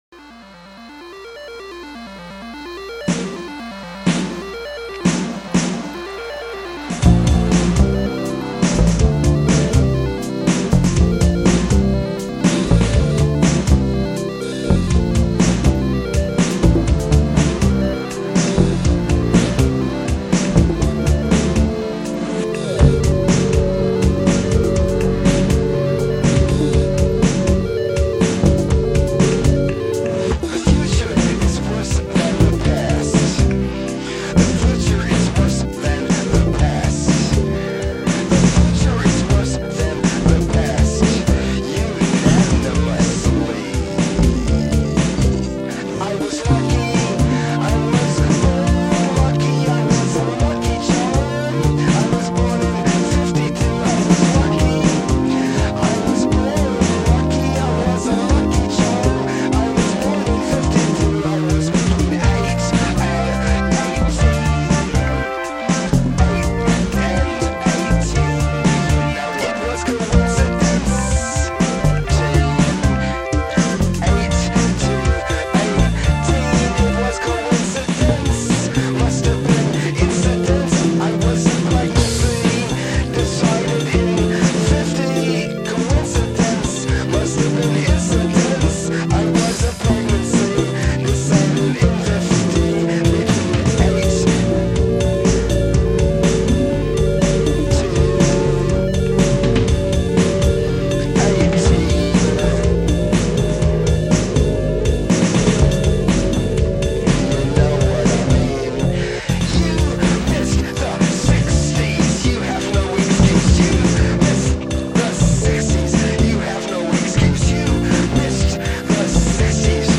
ripped to Lo-Fi MP3 Files